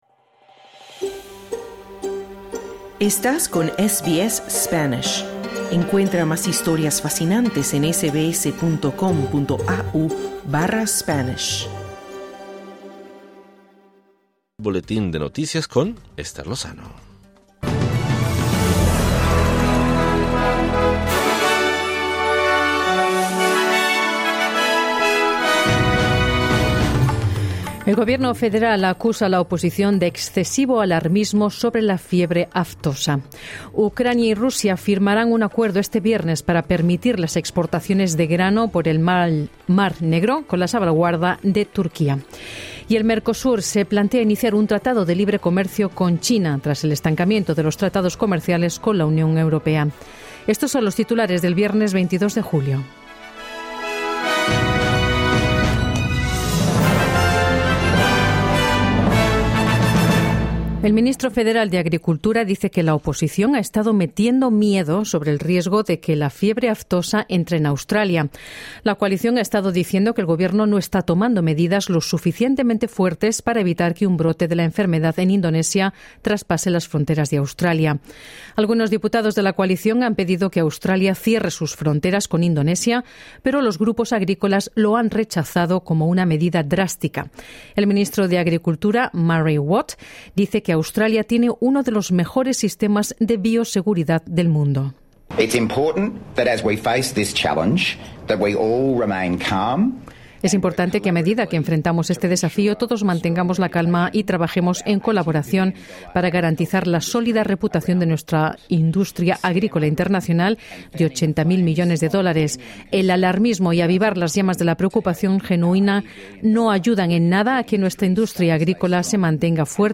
El Mercosur se plantea iniciar un tratado de libre comercio con China, tras el estancamiento de los tratados comerciales con la Unión Europea. Escucha esta y otras noticias importantes del día.